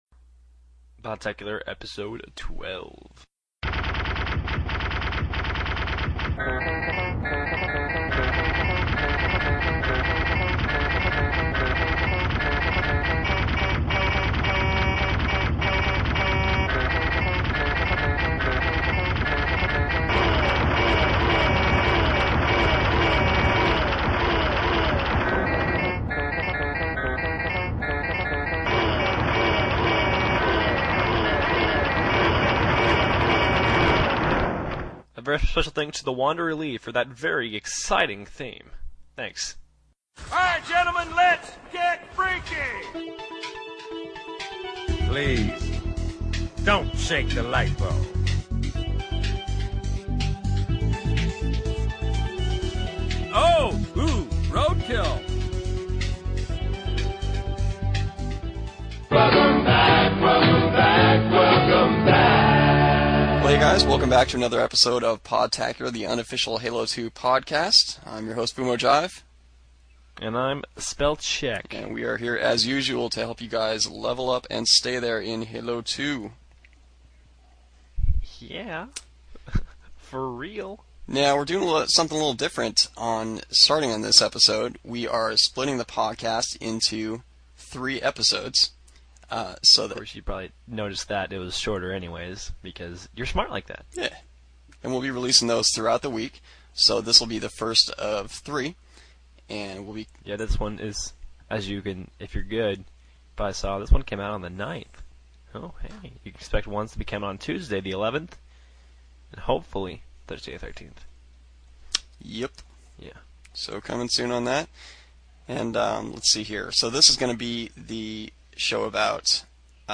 Hope you like our new higher quality shows!